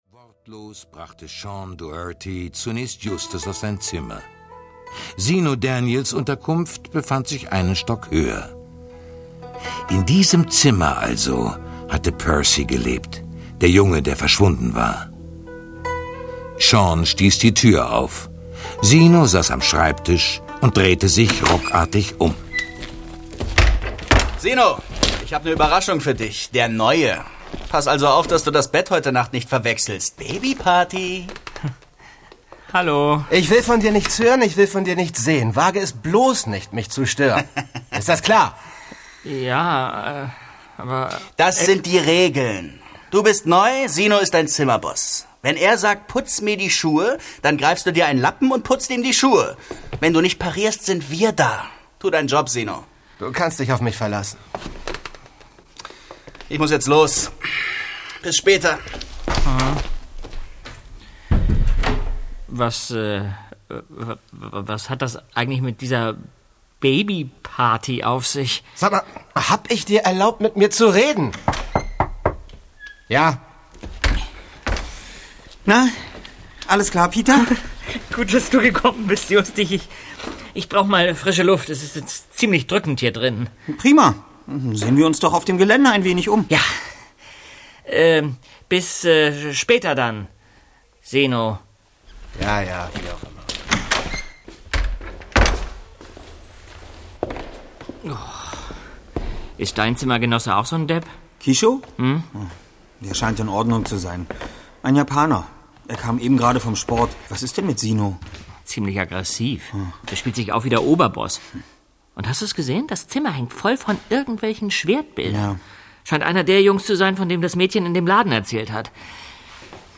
Die drei ??? - Die Rache der Samurai | Physical CD Audio drama
rzähler - Thomas Fritsch